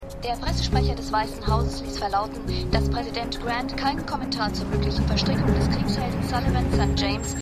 Radiosprecherin [vo]                                        ?
Scandal_1x01_Radiosprecherin.mp3